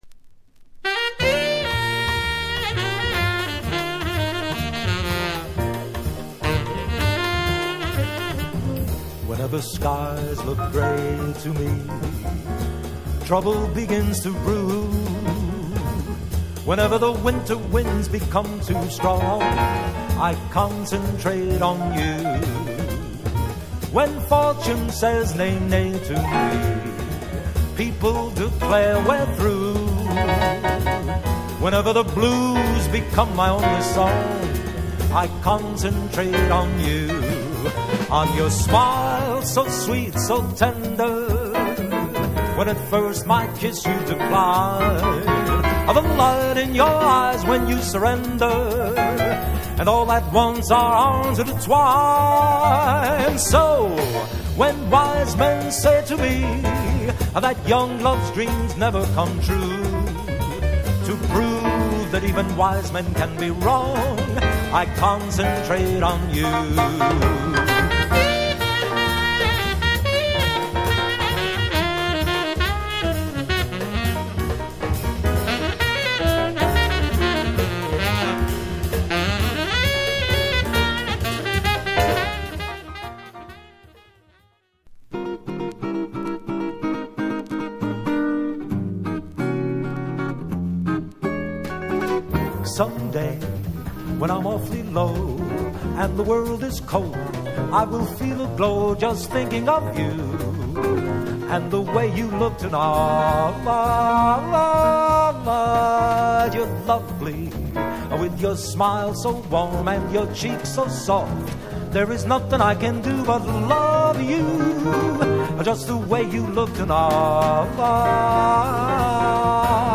'84/8trks.LP mega rare private pressing *dh very small(ex-
コンテンポラリー度は殆どなく、真っ当なジャズボーカルを演ってます。ノリノリのスイング・チューン
ジャジーなギターのアドリブが最高にカッコ良い
スタイリッシュな４ビート・スイング
ジェントルなジャズボサ